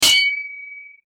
Clash_03.mp3